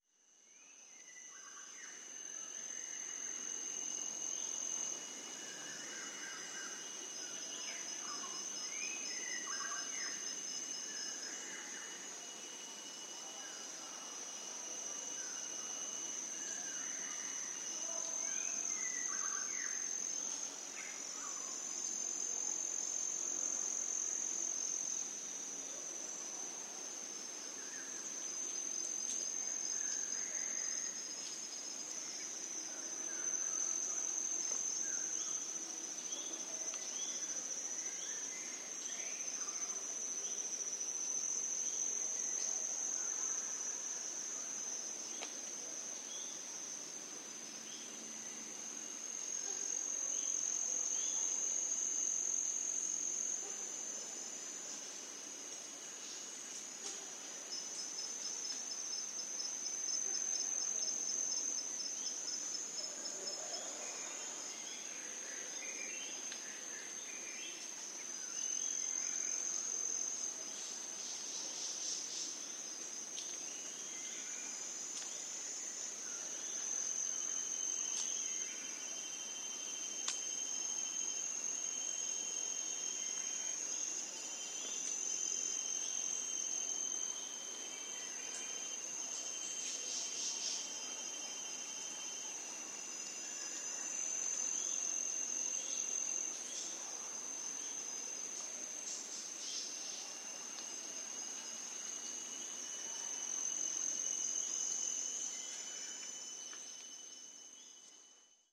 Глубоко в тропическом лесу